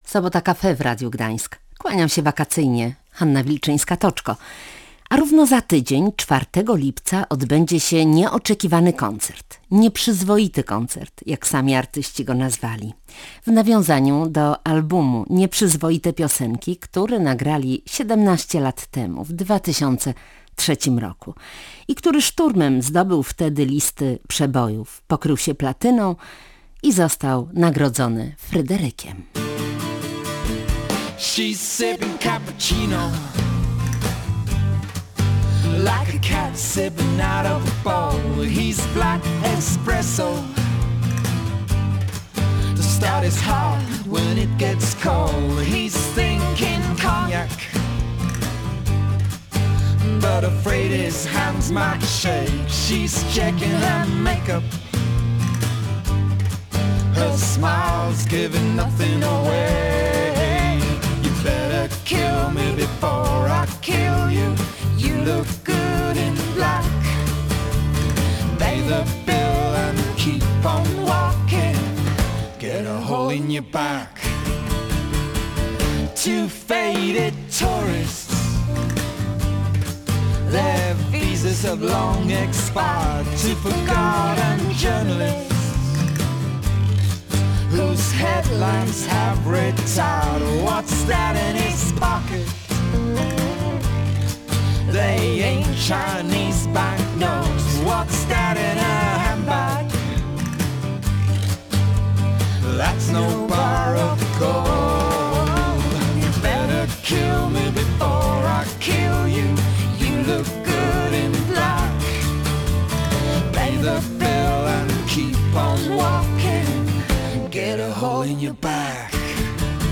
W audycji Sobota cafe Anita Lipnicka opowiada, skąd wziął się pomysł na wspólny koncert z Johnem Porterem, mówi o swoich 45. urodzinach i 70. urodzinach Johna, wraca też do czasu, kiedy byli „najgorętszą” parą w polskim show biznesie.